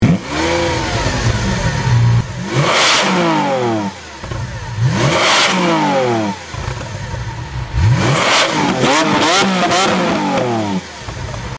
• Качество: 128, Stereo
рёв движка